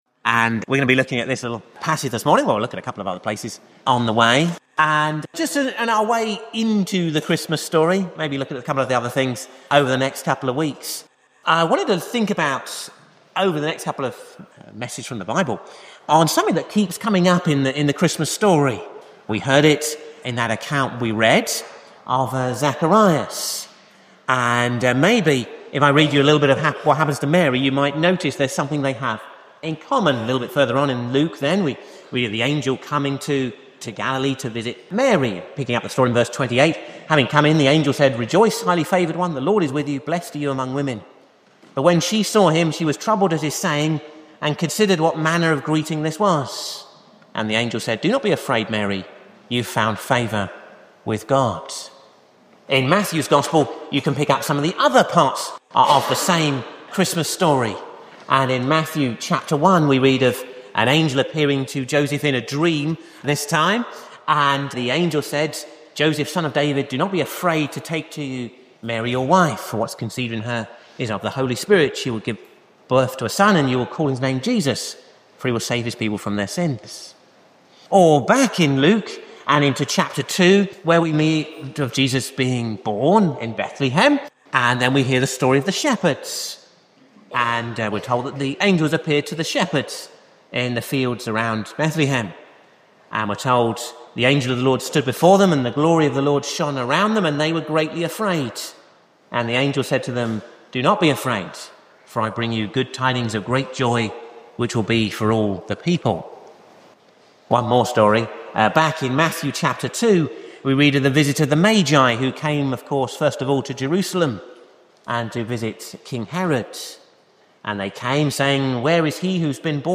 'Do Not Be Afraid' Sermon Series: Ashbourne Baptist Church 2026